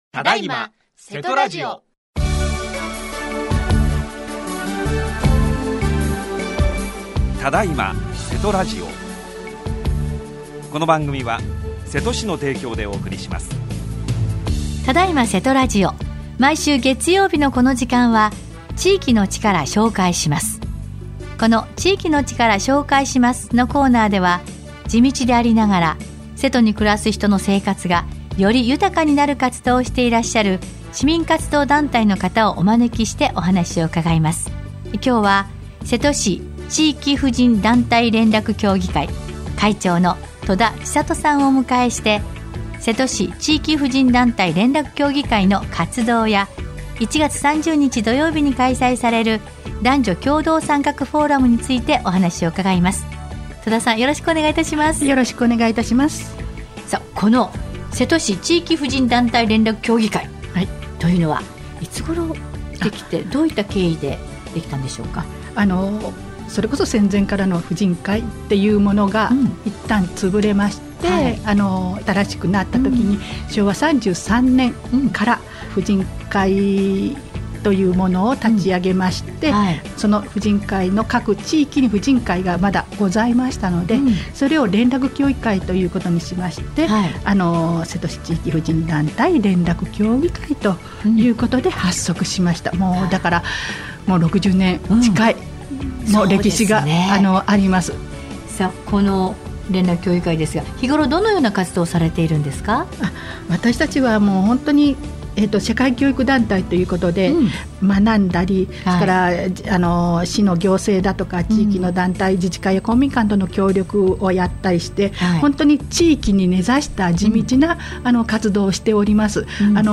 28年1月18日（月） 毎週月曜日のこの時間は、〝地域の力 紹介します〝 このコーナーでは、地道でありながら、 瀬戸に暮らす人の生活がより豊かになる活動をしていらっしゃる 市民活動団体の方をお招きしてお話を伺います。